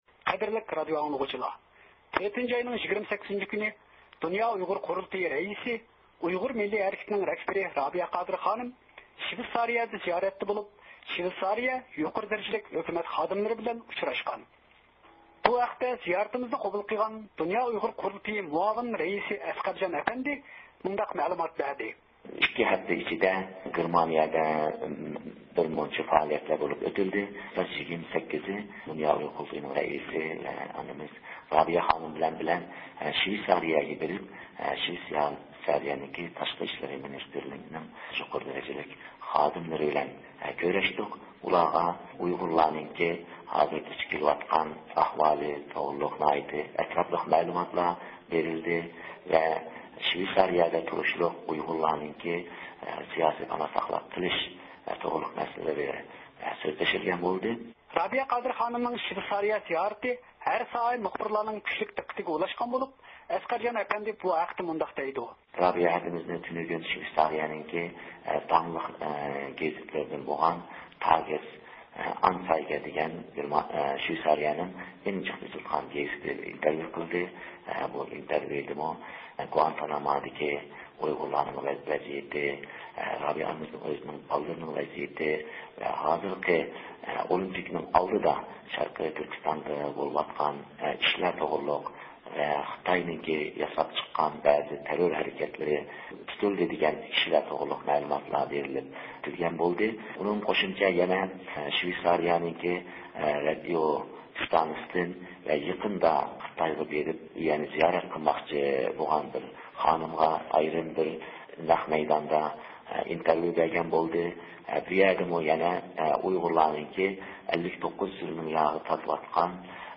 خەۋىرى